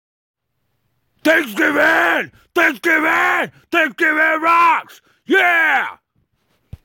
Hilarious Thanksgiving Song - I Hope You Enjoy It!